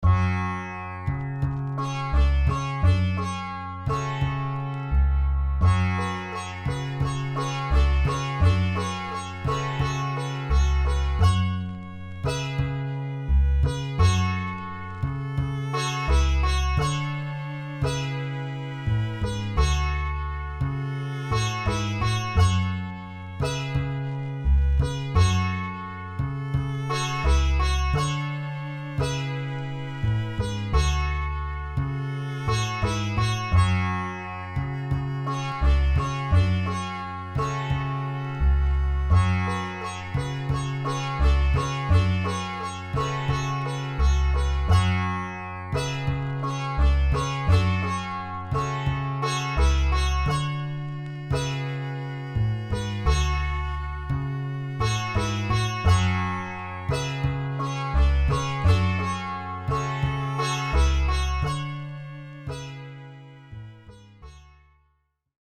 这使其成为寻找亚洲风味的嘻哈音乐制作人的理想采样材料。
•10条完整的器乐曲目